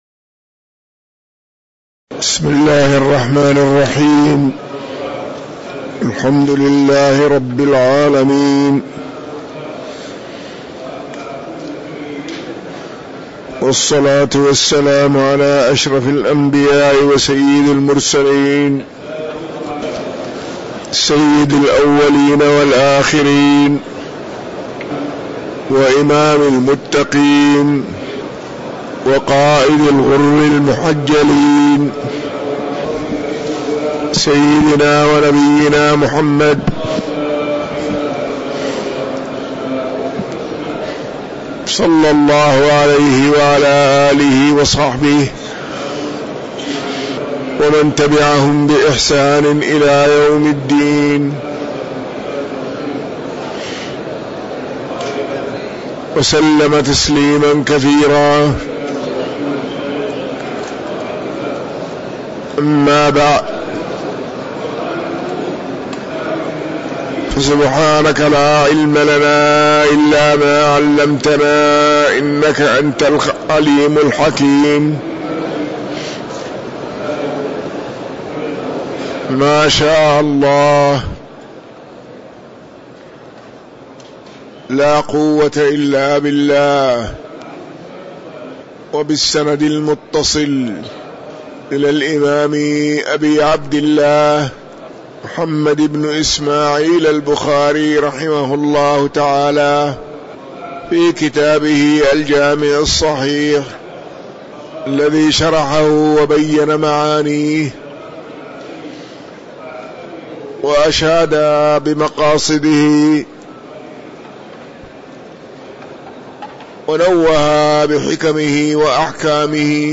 تاريخ النشر ٢٧ شعبان ١٤٤٣ هـ المكان: المسجد النبوي الشيخ